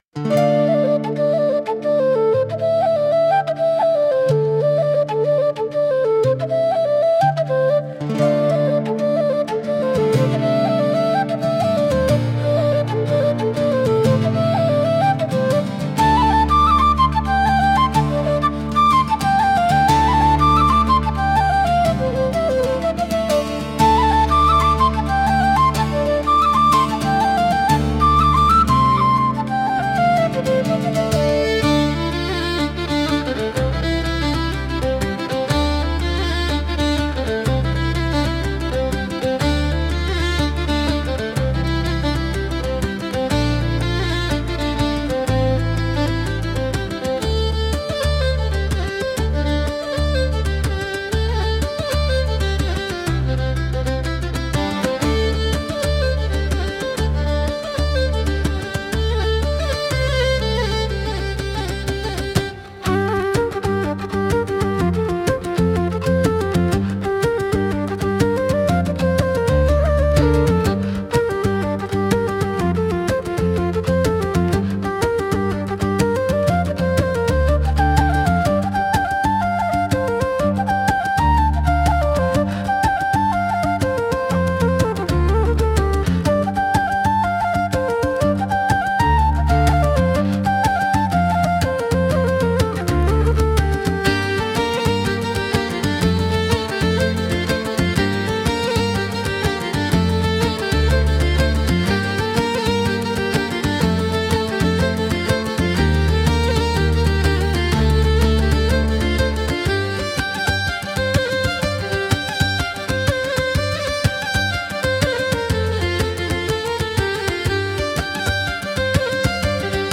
聴く人に親しみやすさと爽やかな感動を届ける民族的で情緒豊かなジャンルです。